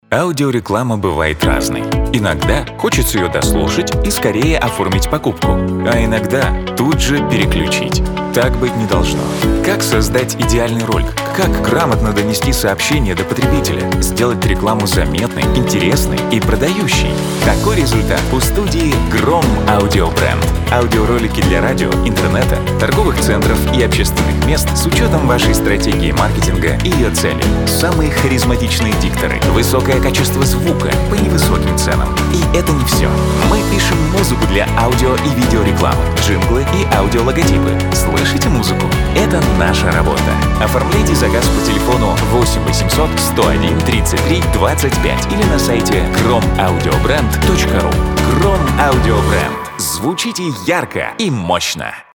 Сведение и мастеринг аудиоролика для рекламы (информационного)
Сведение музыки и голоса диктора, мастеринг